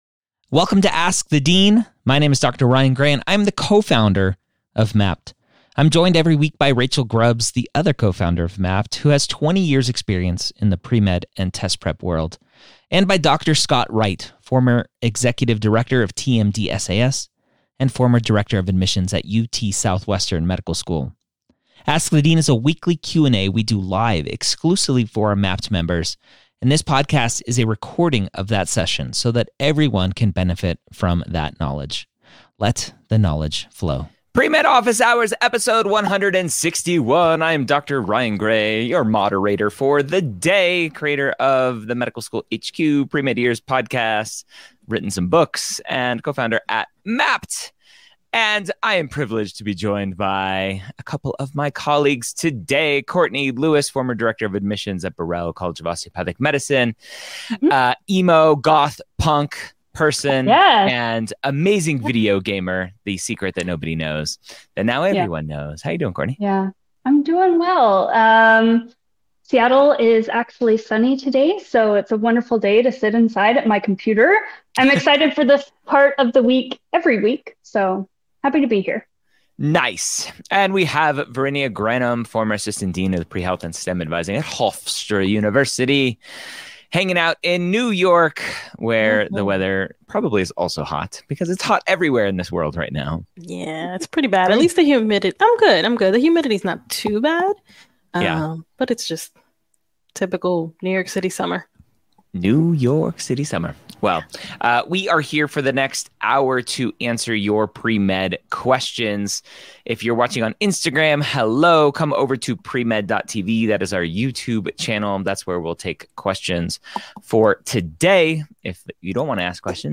Premed Office Hours is a weekly Q&A Podcast
Ask the Dean is recorded live for our Mappd students and put out later for everyone else.